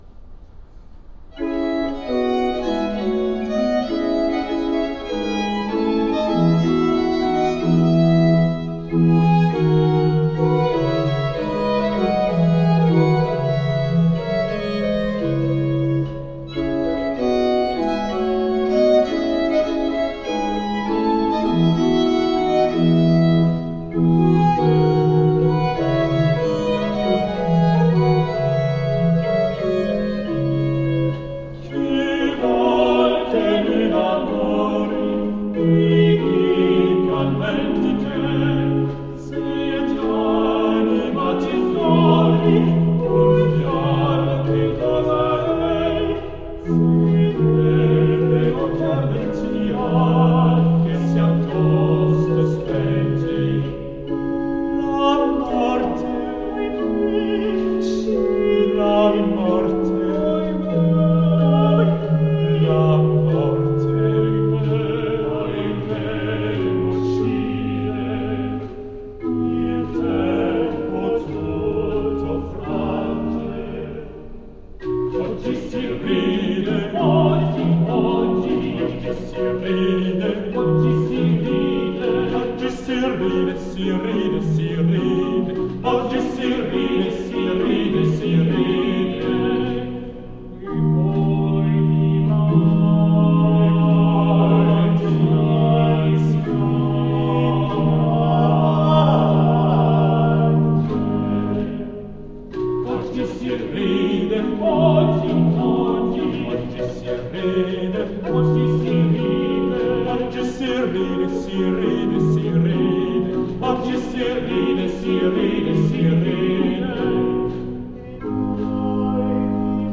Concert du département de musique ancienne, enregistré à l'église Notre-Dame-de-Vertus à Aubervilliers, en mars 2014